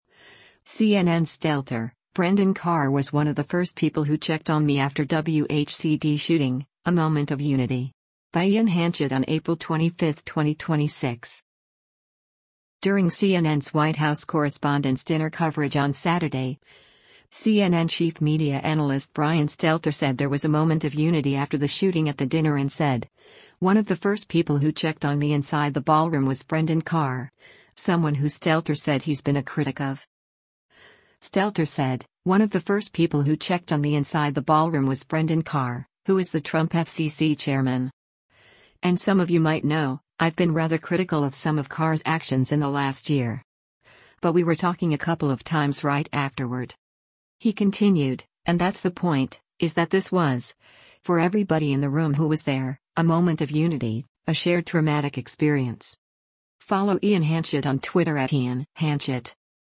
During CNN’s White House Correspondents’ Dinner coverage on Saturday, CNN Chief Media Analyst Brian Stelter said there was “a moment of unity” after the shooting at the dinner and said, “One of the first people who checked on me inside the ballroom was Brendan Carr,” someone who Stelter said he’s been a critic of.